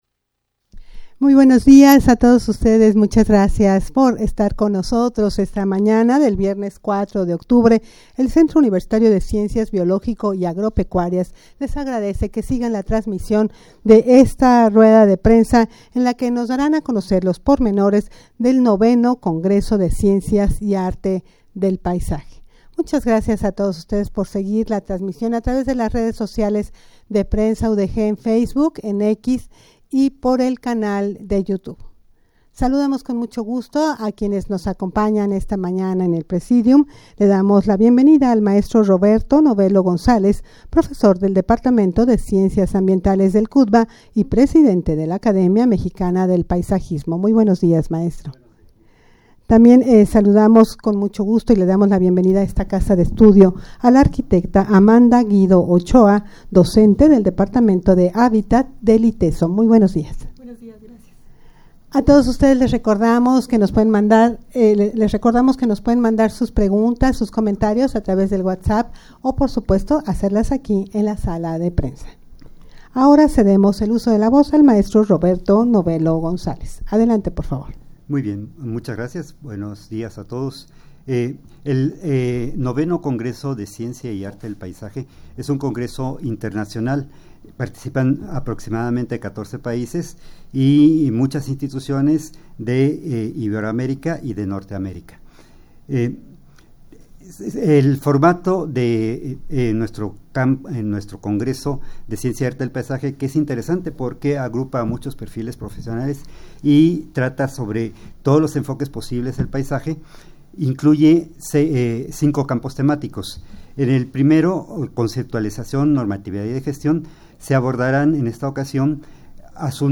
rueda-de-prensa-para-dar-a-conocer-los-pormenores-del-ix-congreso-de-ciencias-y-arte-del-paisaje.mp3